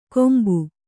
♪ kombu